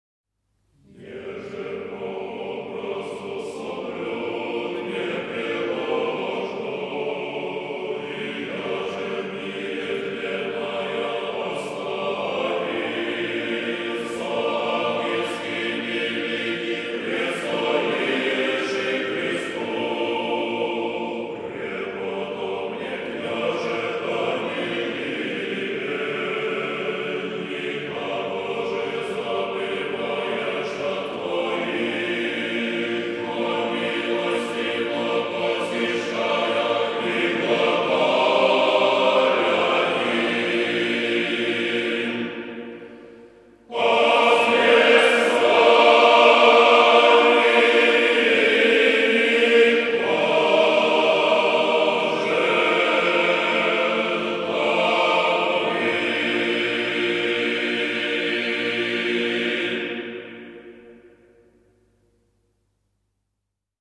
Аудиокнига Всенощное бдение | Библиотека аудиокниг